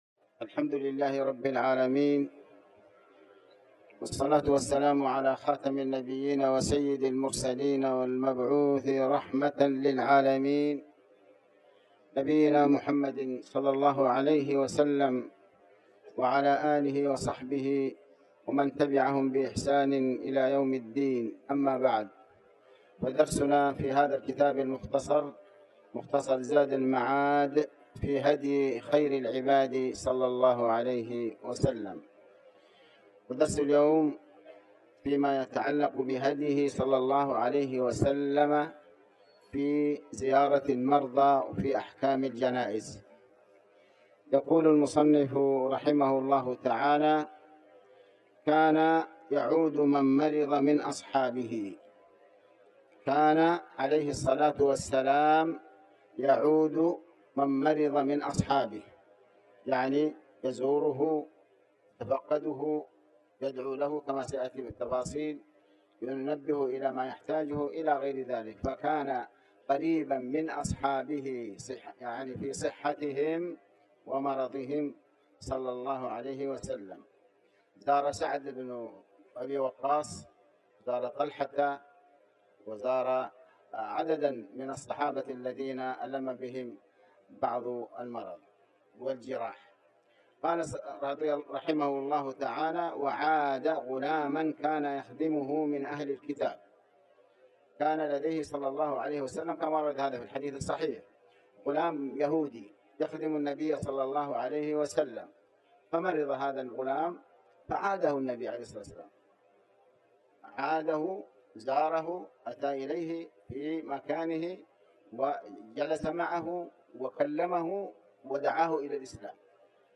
تاريخ النشر ١ جمادى الآخرة ١٤٤٠ هـ المكان: المسجد الحرام الشيخ: علي بن عباس الحكمي علي بن عباس الحكمي هديه صلى الله عليه وسلم في زيارة المرضى